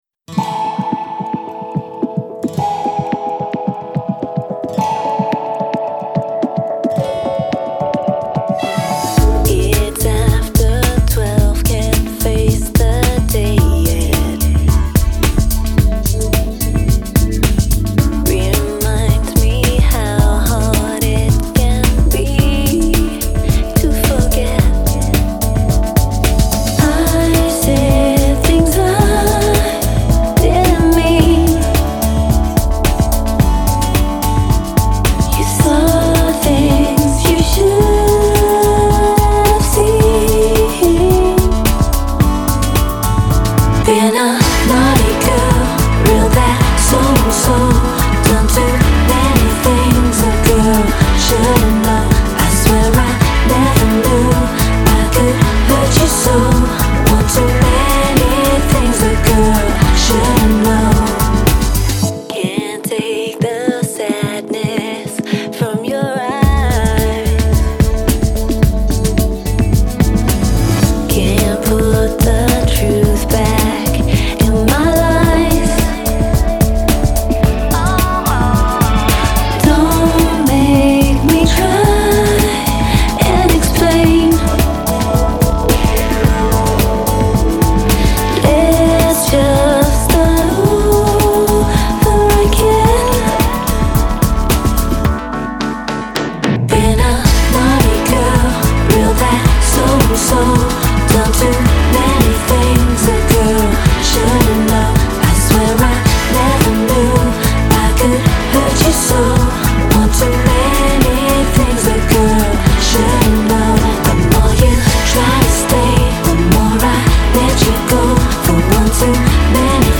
专辑风格：Pop
挑逗意味浓厚的节奏蓝调曲